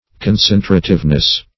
Search Result for " concentrativeness" : The Collaborative International Dictionary of English v.0.48: Concentrativeness \Con*cen"tra*tive*ness\, n. 1. The quality of concentrating.